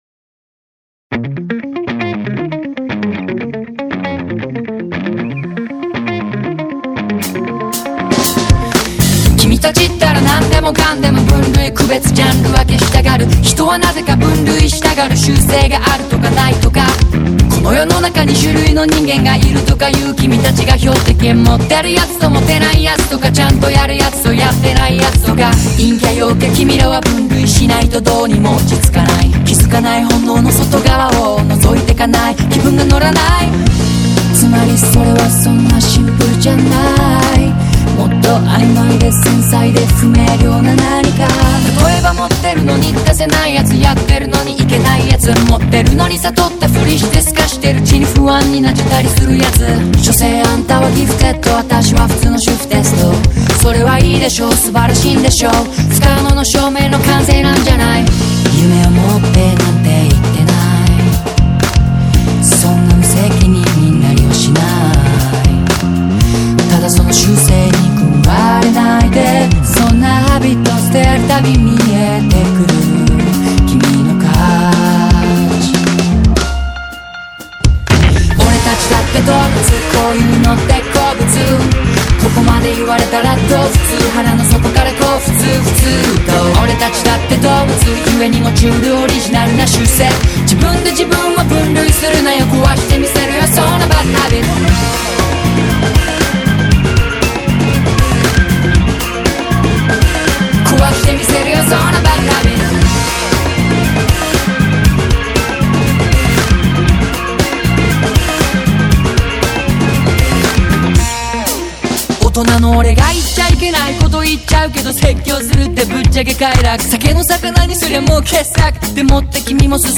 pop, rock, and electronic music elements
With its upbeat tempo and engaging instrumentation